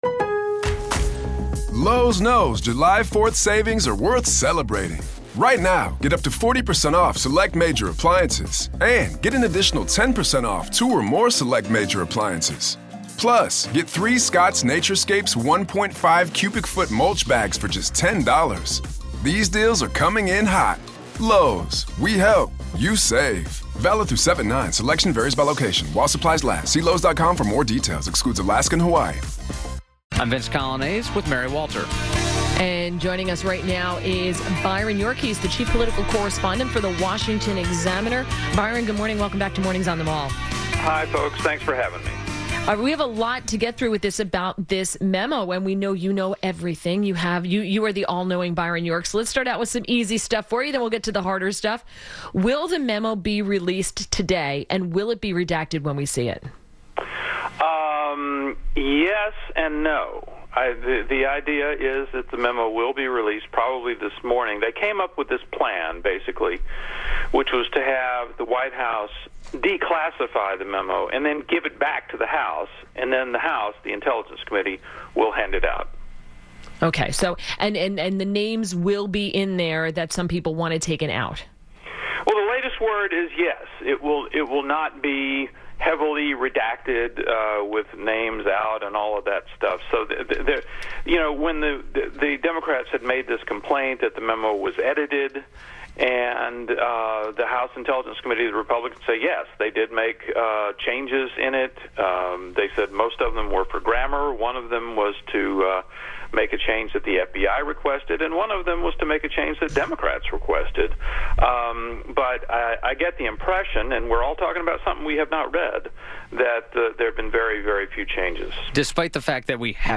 WMAL Interview - BYRON YORK - 02.02.18
INTERVIEW – BYRON YORK – Chief political correspondent for the Washington Examiner – discussed the latest news on the memo.